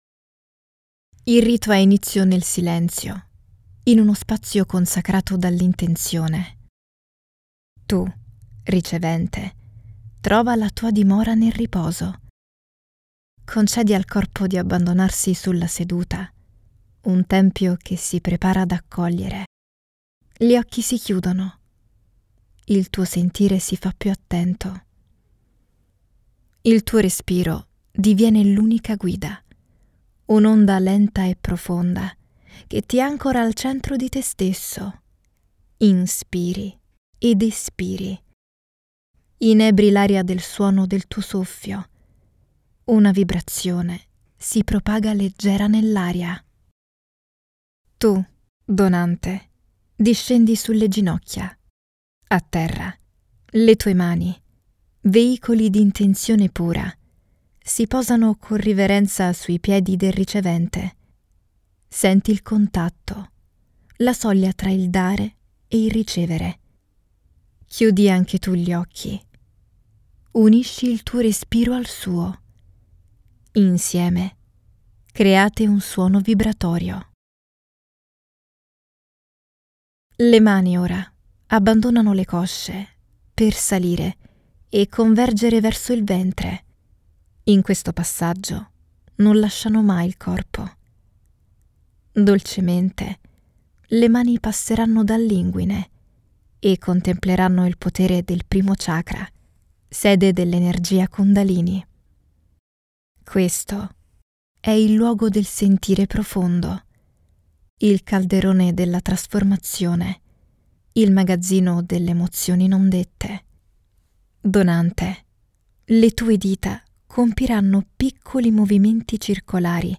Female
Meditation
Words that describe my voice are Engage, Natural, Energic.
All our voice actors have professional broadcast quality recording studios.